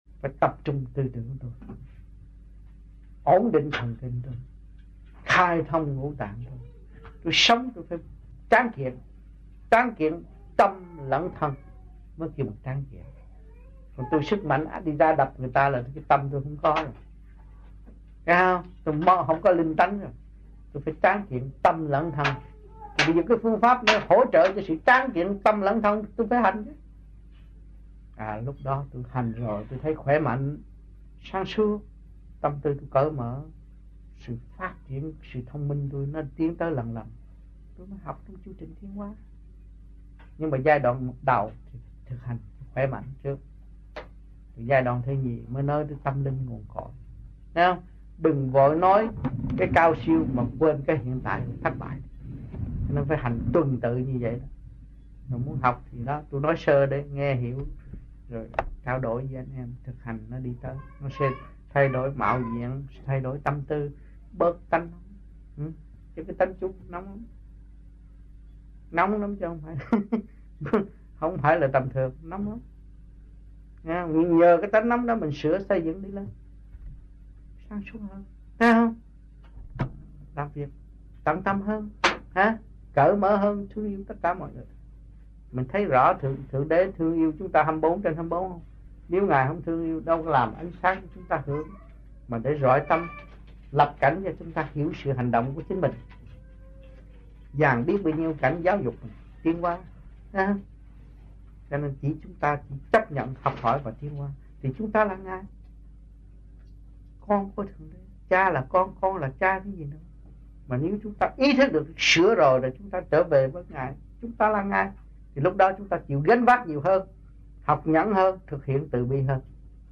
Amphion les Bains, France Trong dịp : Sinh hoạt thiền đường >> wide display >> Downloads